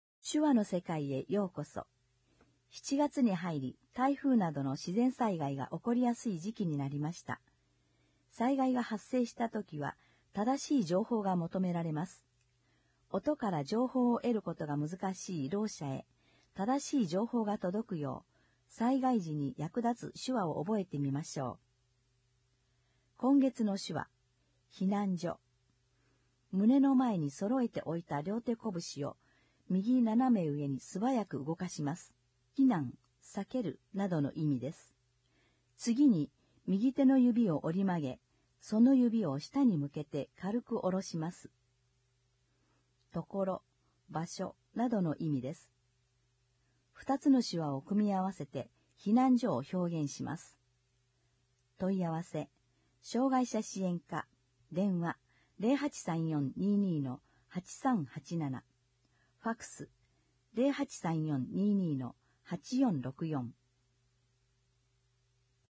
音訳広報